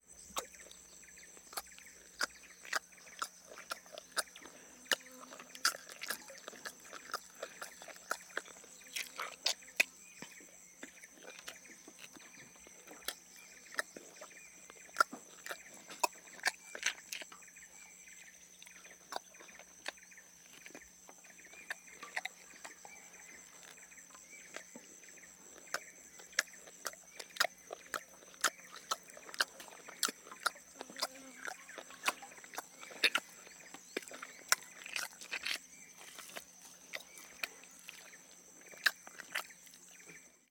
Чавканье